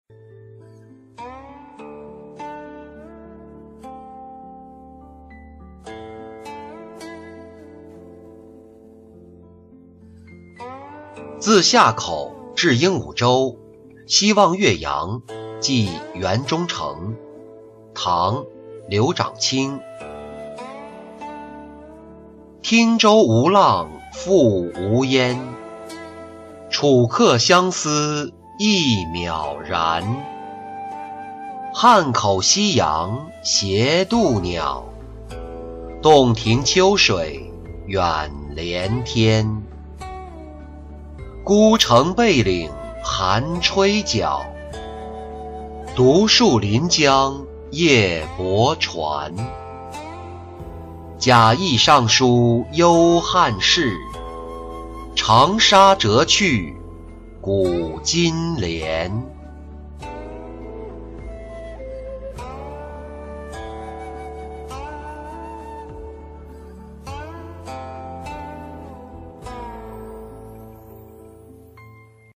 自夏口至鹦鹉洲夕望岳阳寄源中丞-音频朗读